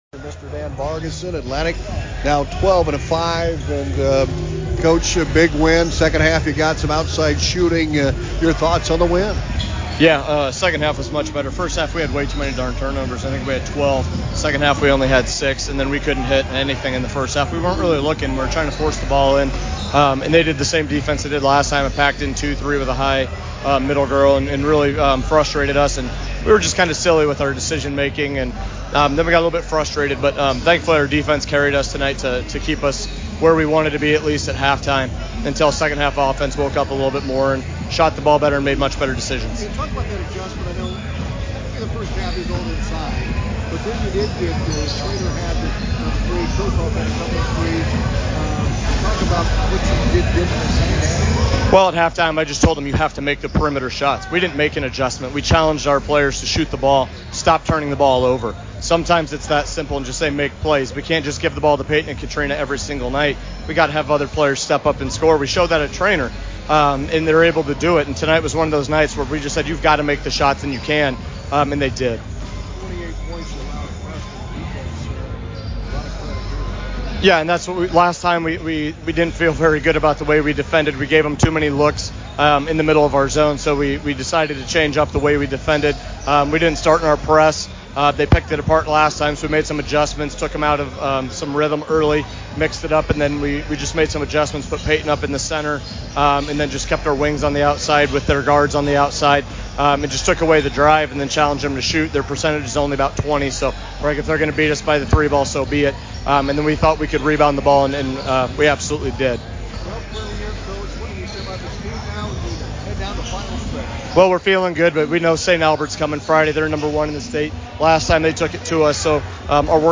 post game comments: